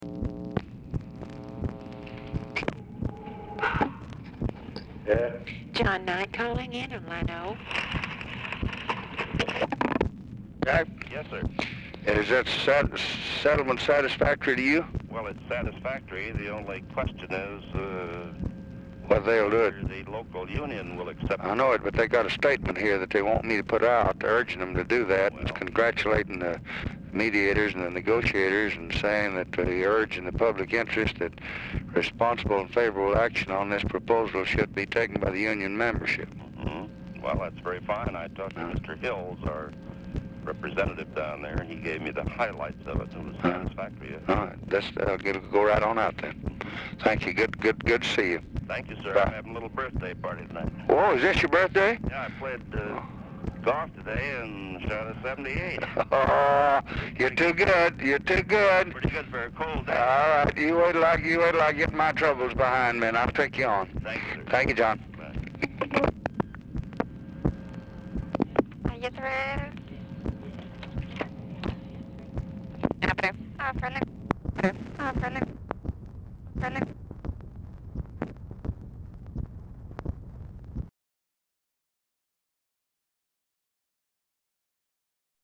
Telephone conversation
Format Dictation belt
Location Of Speaker 1 Oval Office or unknown location